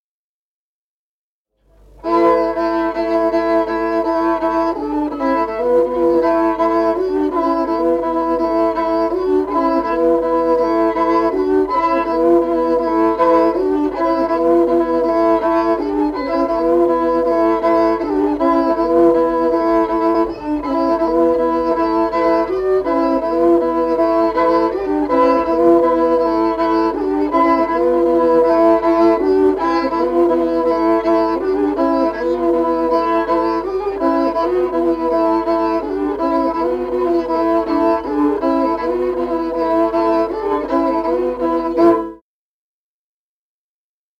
Музыкальный фольклор села Мишковка «Русский», партия 2-й скрипки.